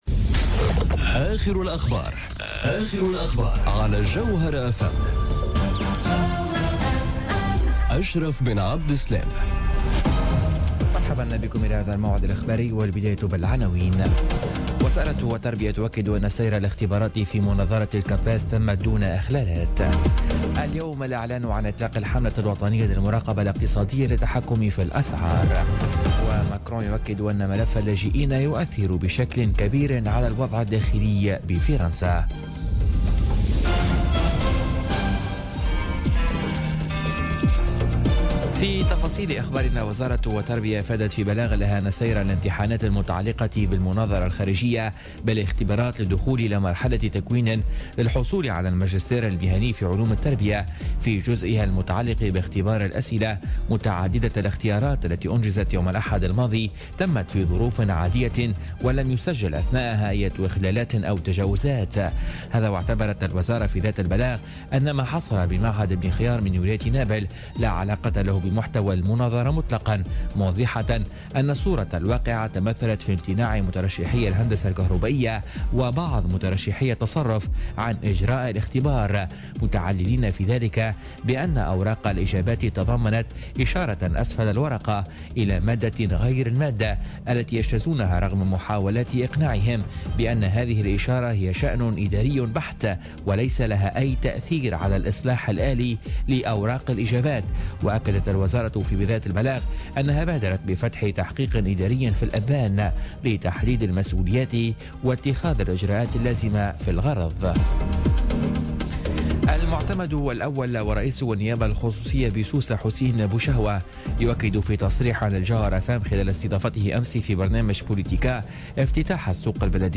نشرة أخبار منتصف الليل ليوم الثلاثاء 16 ماي 2017